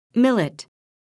[mílit]